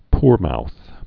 (prmouth, -mouth)